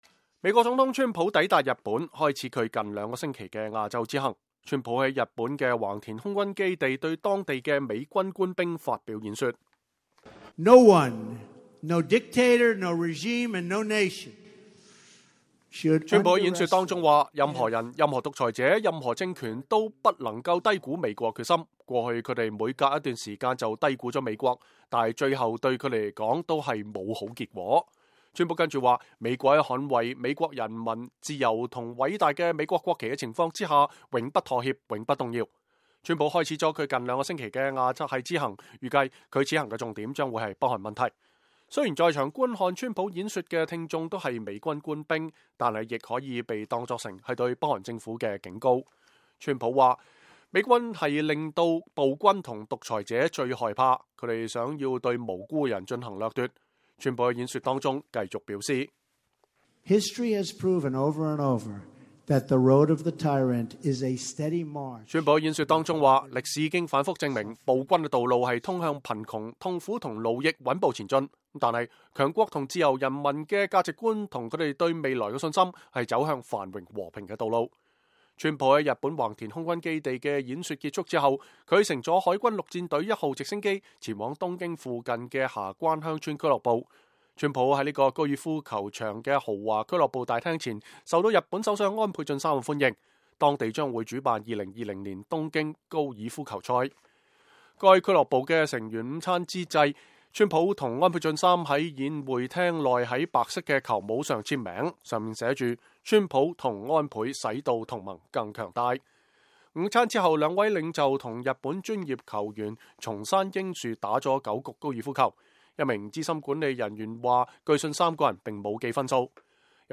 美國總統川普抵達日本，開始他近兩個星期的亞洲之行。川普在日本橫田空軍基地對當地的美軍官兵發表演說。